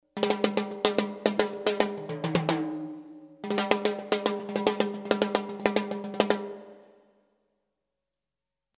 Timbales fills in 110 bpm
The timbales are with light reverb and they are in 110 bpm.
This package contains real timbales fills playing a variety of fills in 110 bpm.
The free 5 samples are already with nice reverb.
The timbales were recorded using “ AKG C-12 ” mic. The timbales were recorded mono but the files are stereo for faster workflow. The reverb is stereo on the mono timbales.